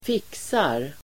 Uttal: [²f'ik:sar]